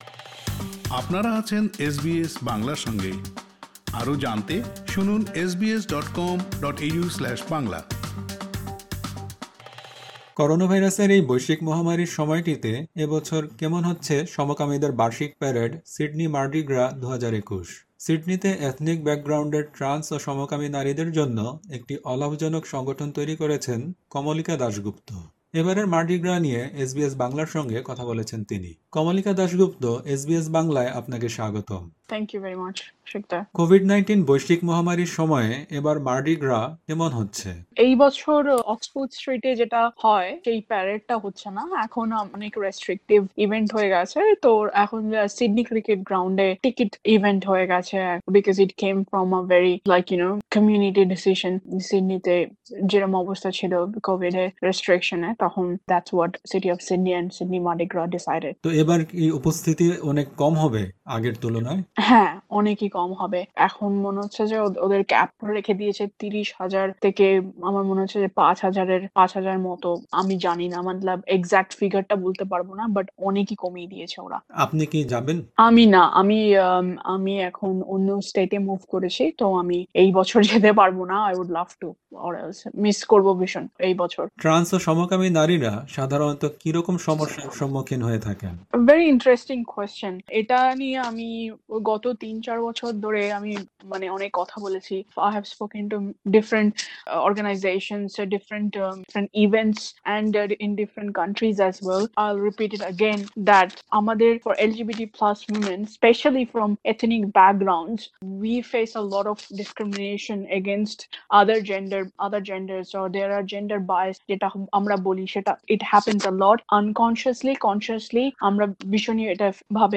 সমকামীদের বার্ষিক প্যারেড ‘সিডনি মার্ডি গ্রা ২০২১’ উপলক্ষে এসবিএস বাংলার সঙ্গে কথা বলেছেন